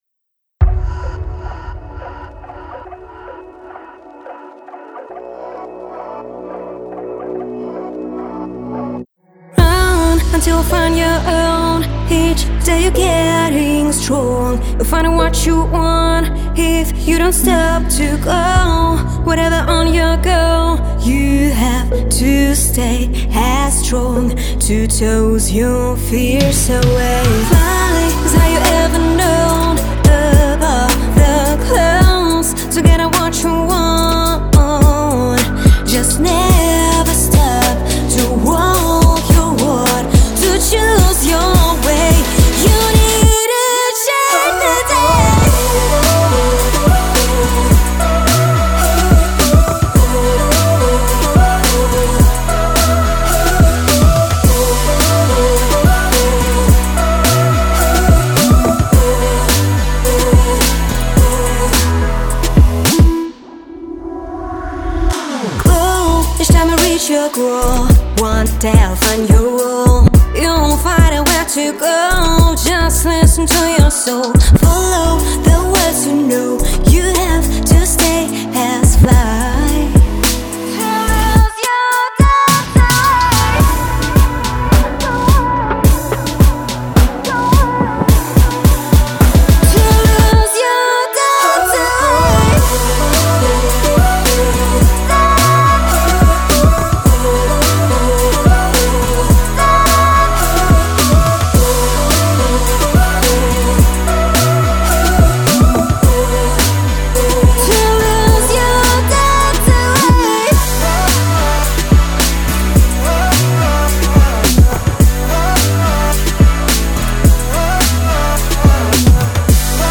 мотиваційний трек